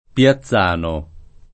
[ p L a ZZ# no ]